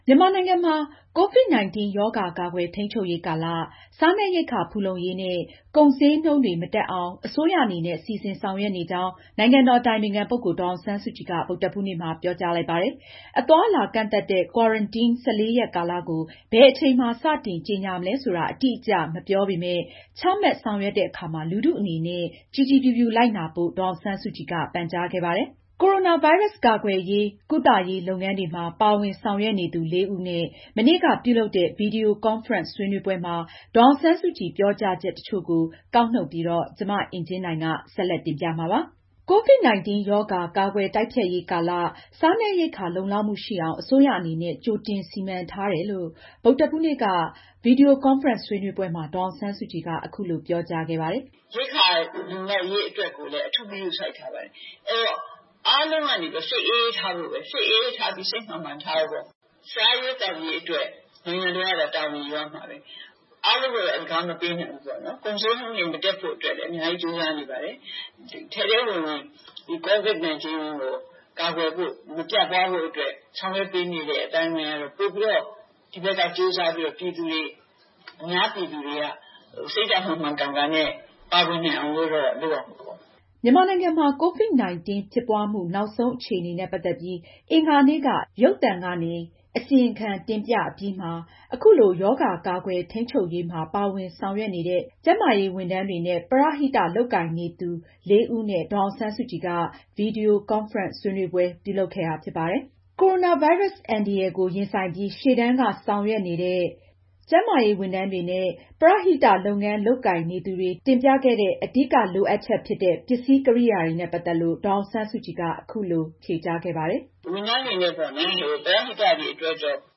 COVID-19 ရောဂါ ကာကှယျ တိုကျဖကြျရေးကာလ စားနပျရိက်ခာ လုံလောကျမှုရှိအောငျ အစိုးရအနနေဲ့ ကွိုတငျစီမံထားတယျလို့ ဗုဒ်ဓဟူးနေ့က ဗှီဒီယို ကှနျဖရင့ျဆှေးနှေးပှဲမှာ ဒေါျအောငျဆနျးစုကွညျက အခုလို ပွောကွားခဲ့ပါတယျ။